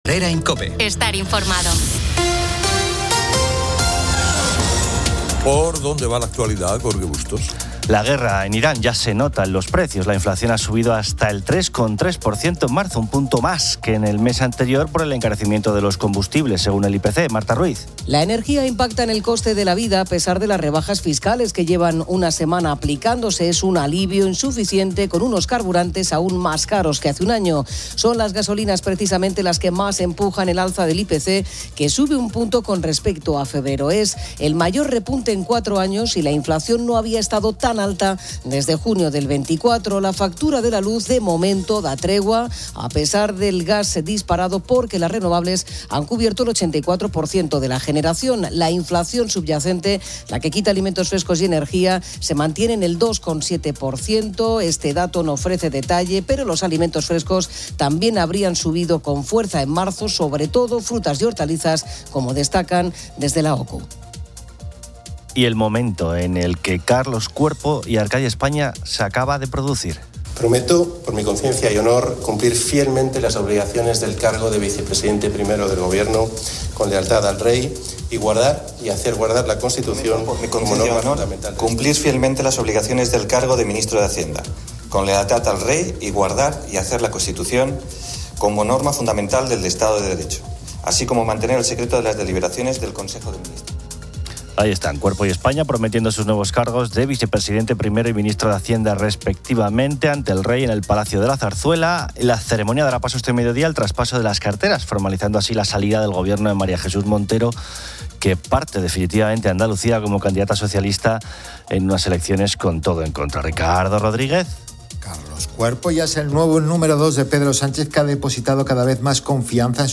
El programa dedica gran atención a la Semana Santa española. Oyentes y colaboradores comparten sus vivencias y tradiciones de procesiones en localidades como Quintanar del Rey, Priego de Córdoba, Cardeñosa, Gandía y Nava del Rey.